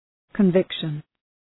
{kən’vıkʃən}